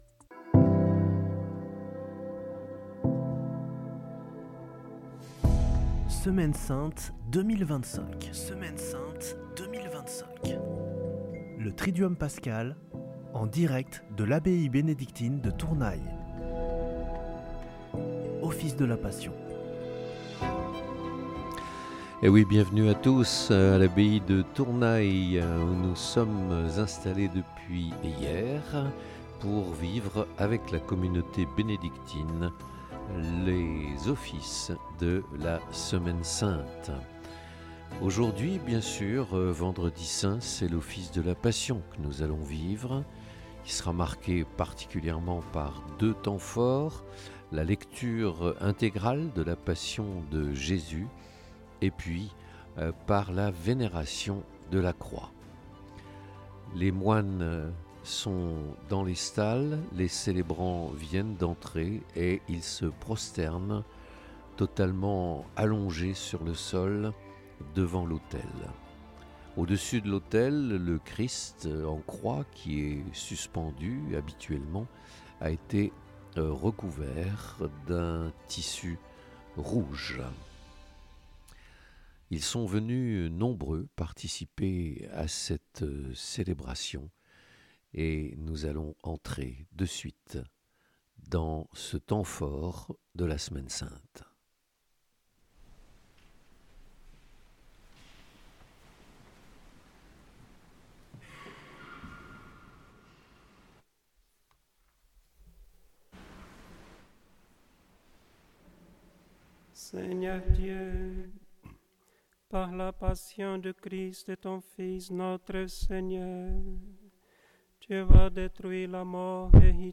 En direct, depuis l’abbaye bénédictine de Tournay dans les Hautes-Pyrénées.